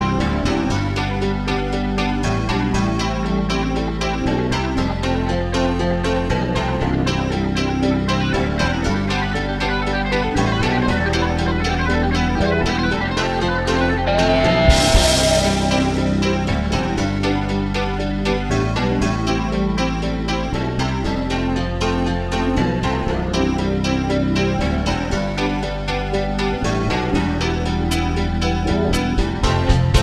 Four Semitones Down Rock 4:28 Buy £1.50